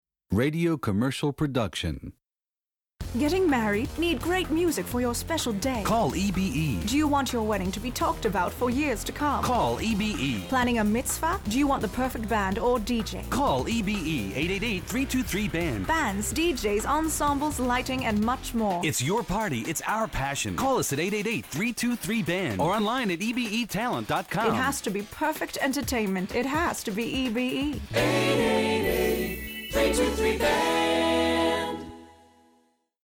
Voice Over
Radio Spot
radio_spot.mp3